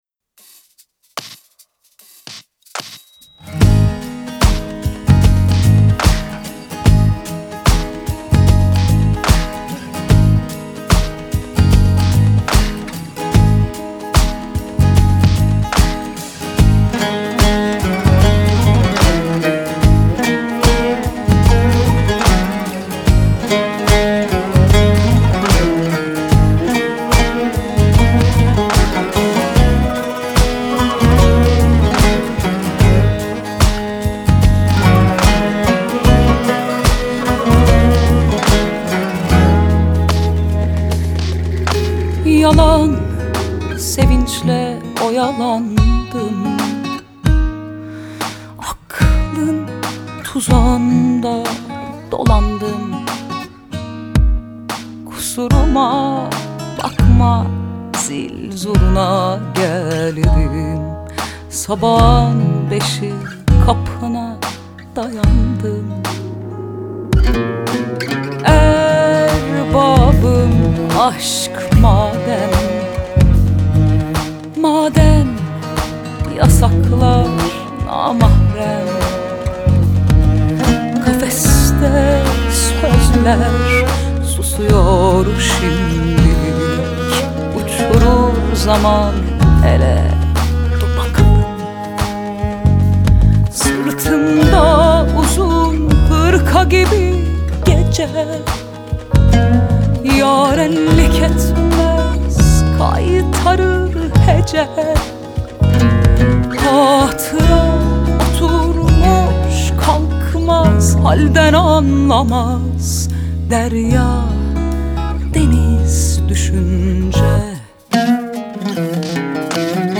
ترانه ترکی استانبولی türkçe şarkı